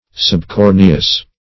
Search Result for " subcorneous" : The Collaborative International Dictionary of English v.0.48: Subcorneous \Sub*cor"ne*ous\, a. (Anat.)